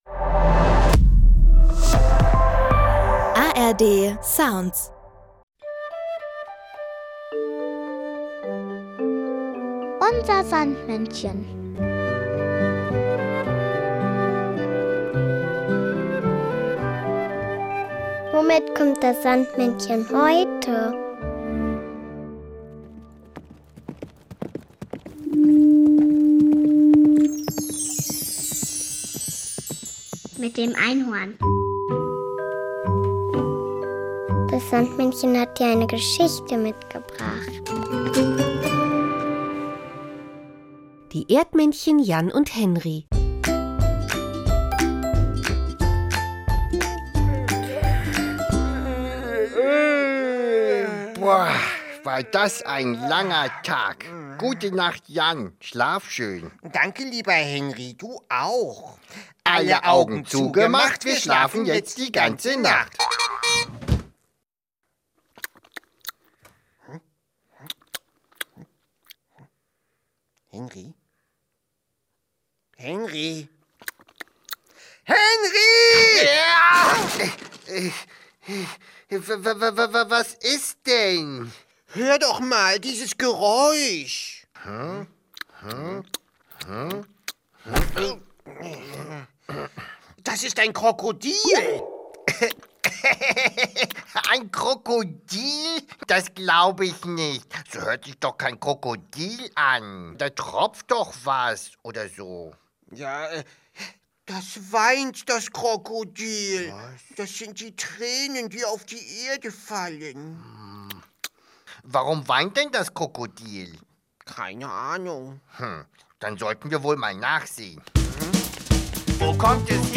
noch das Kinderlied "Der Maulwurf" von Libatiba.